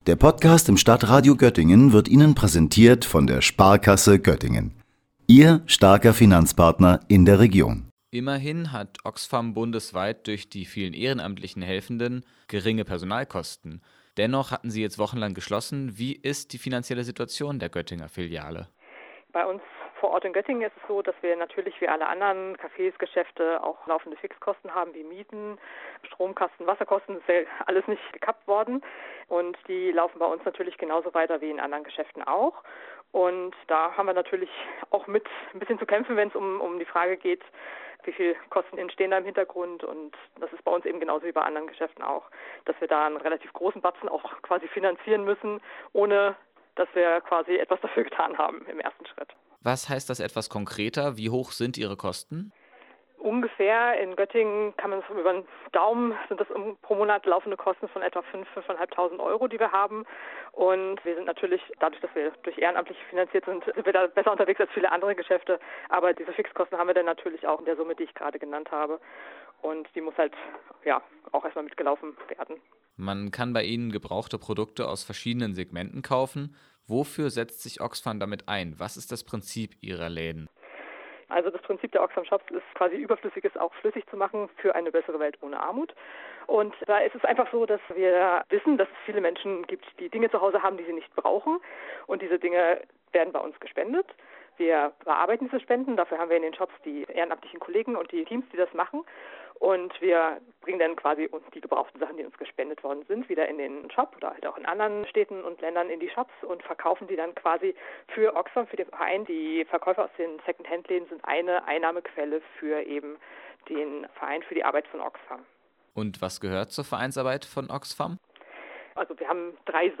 Allerdings sind die Öffnungszeiten eingeschränkt, da ein großer Teil der Ehrenamtlichen im Laden älter ist und zur Risikogruppe gehört. Über die finanzielle und personelle Situation hören Sie ein Gespräch